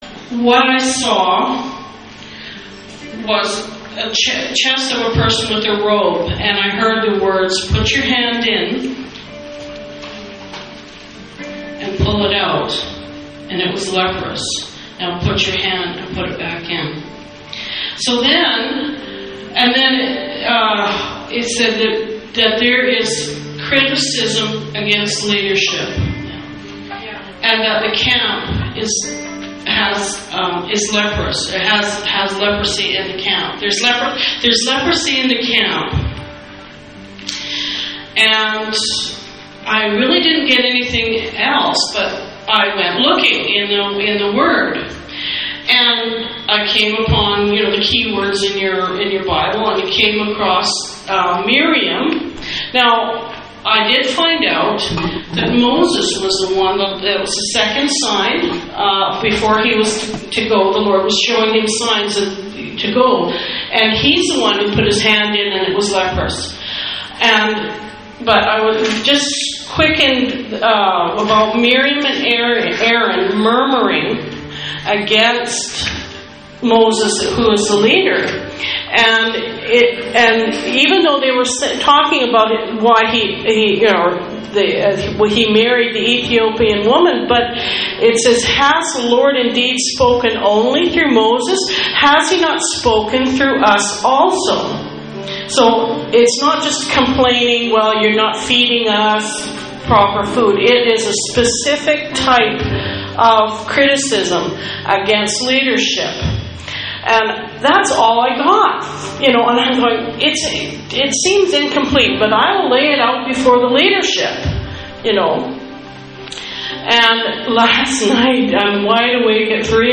RECENT PROPHETIC WORDS & PRAYERS IN AUDIO:
From Vernon Valleywide Gathering, 5th May, 2012: